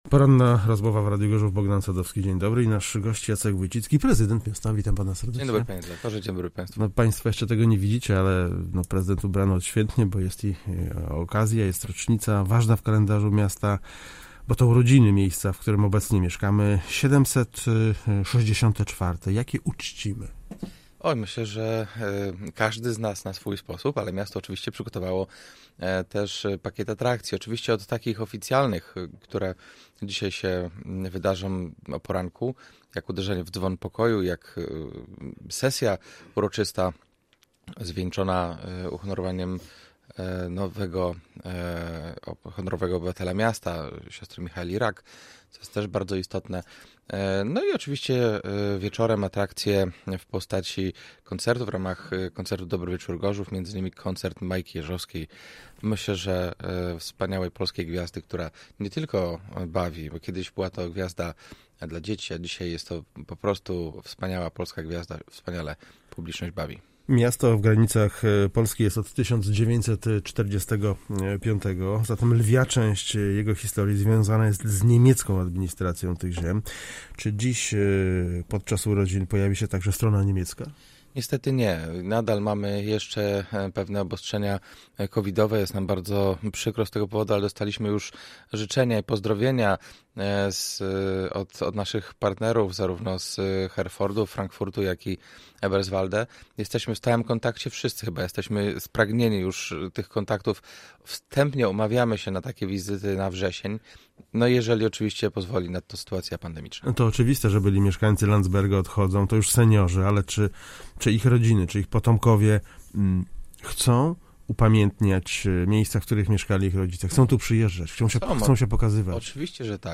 Poranna-rozmowa-2.07-prezydent-J.Wojcicki.mp3